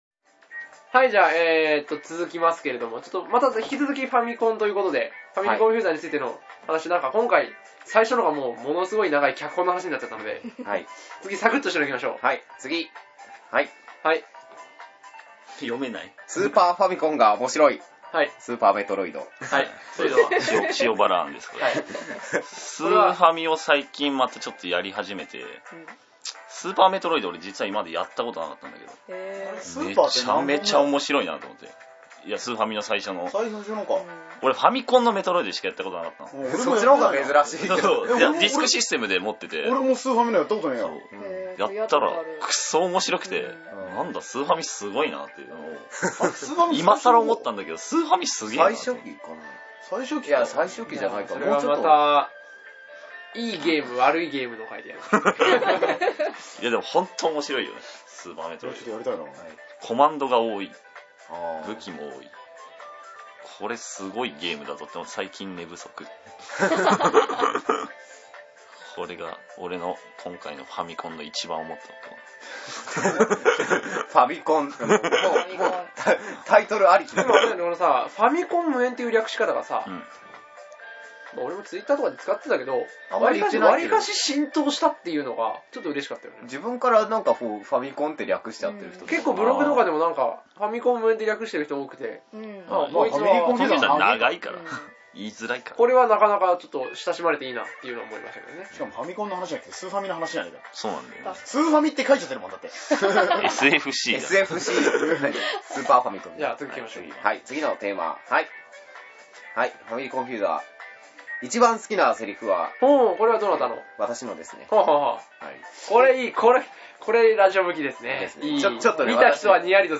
深夜から一気に長時間録り。 公演についてをライトにふりかえるつもりがたまにヘビーになったり。